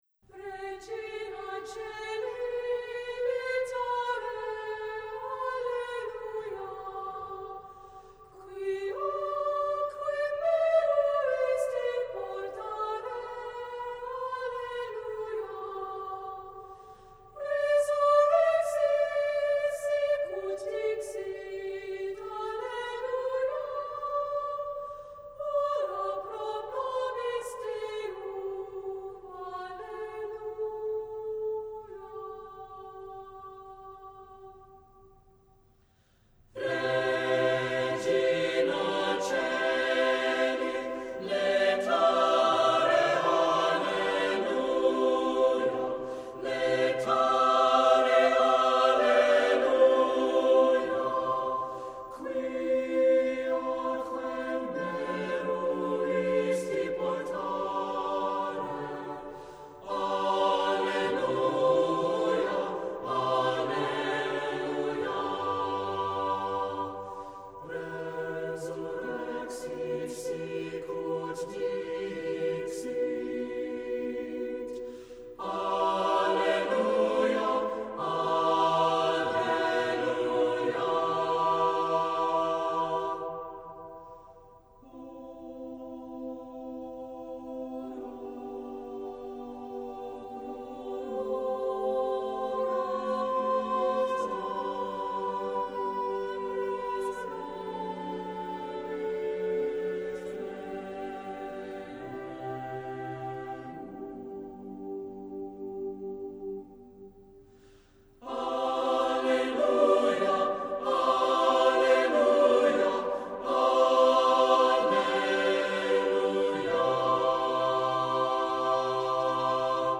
Voicing: Unison; SATB; SATB Double Choir